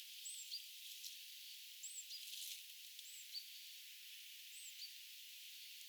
tuollaista ääntä
kuusitiainen piti matkatessaan etelään
tuollaista_aanta_kuusitiaislintu_piti_matkatessaan_etelaan.mp3